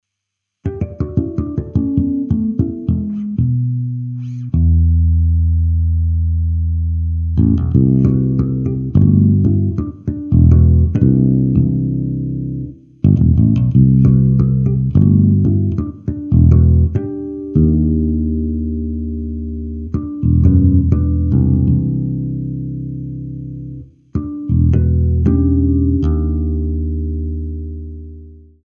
Guitar Rig 2 for some extra drive and some amp "air"